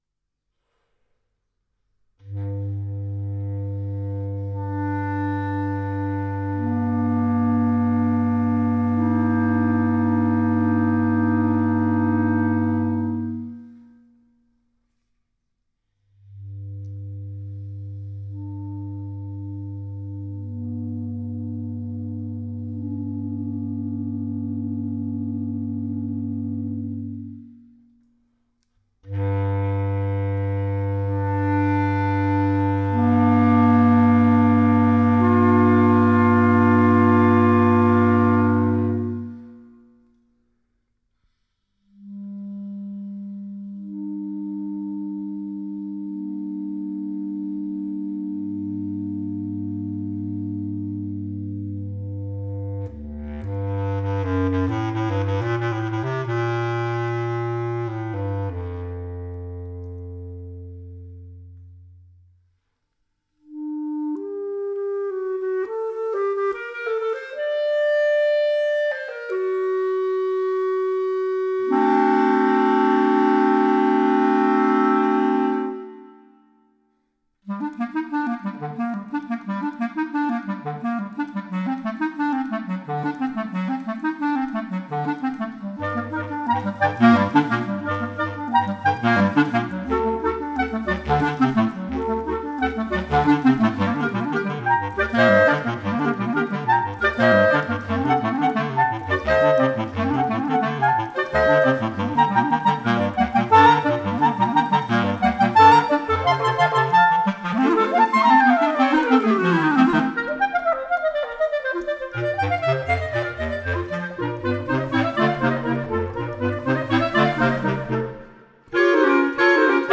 for clarinet quartet